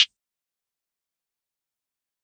Closed Hats
SOUTHERN CHARM HAT.wav